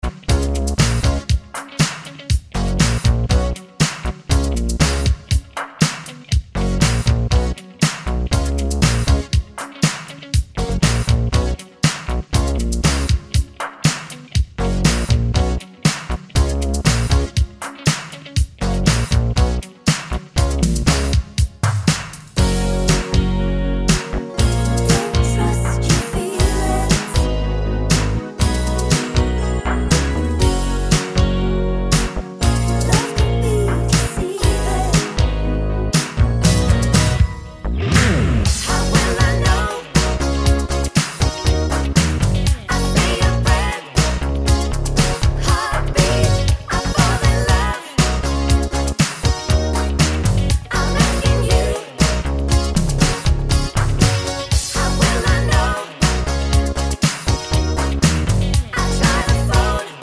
Minus Sax Solo Pop (1970s) 4:41 Buy £1.50